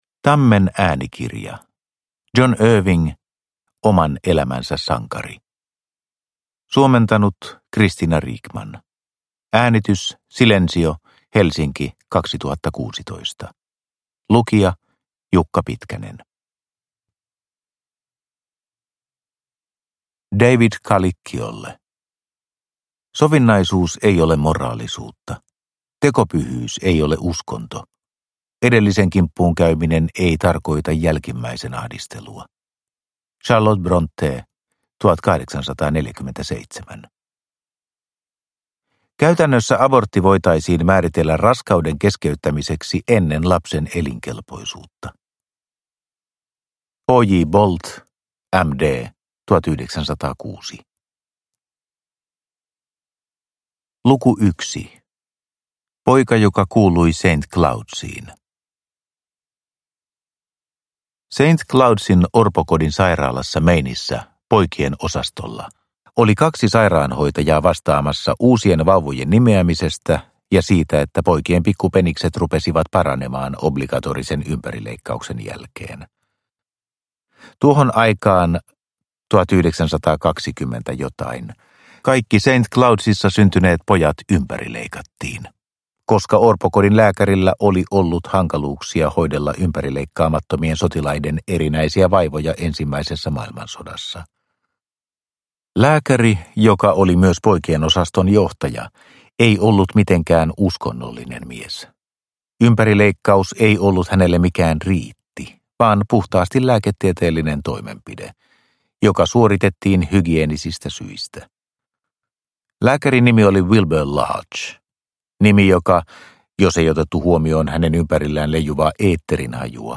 Oman elämänsä sankari – Ljudbok – Laddas ner